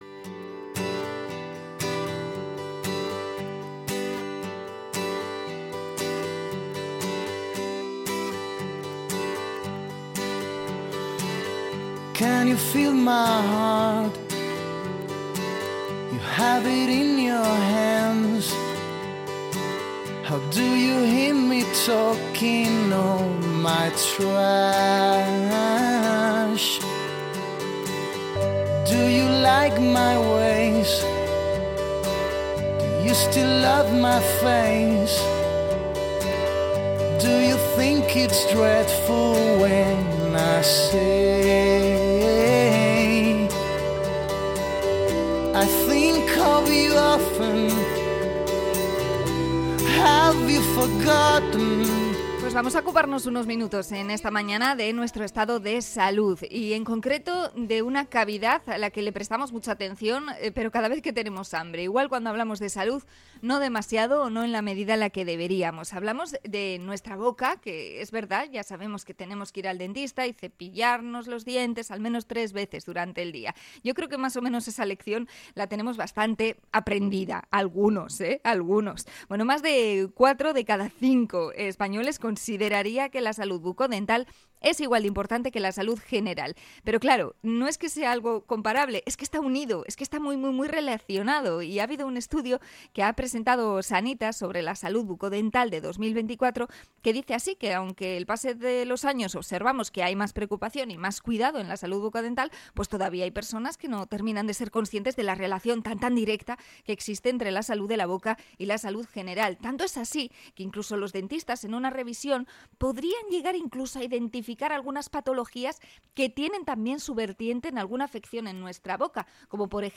Entrevista a internista sobre enfermedades y sus síntomas en la boca